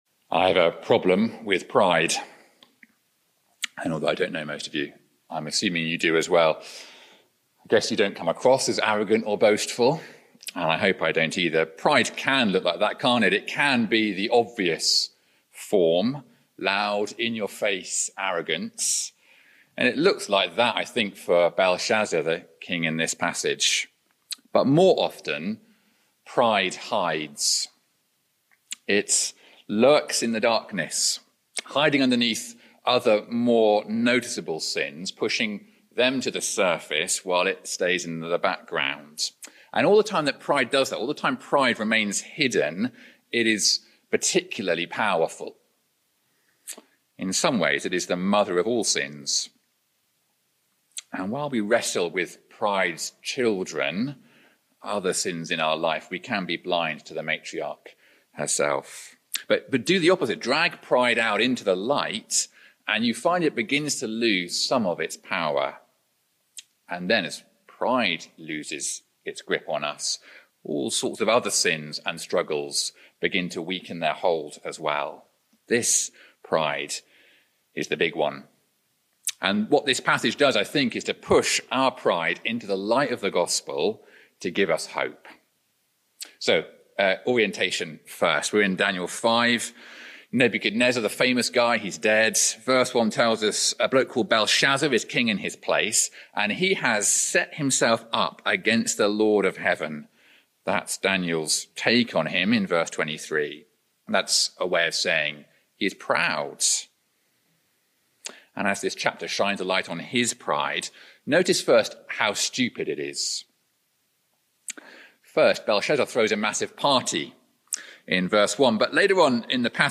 In this Oak Hill College Chapel talk, we explore Daniel 5 and the fall of Belshazzar — a king whose pride looked obvious and foolish.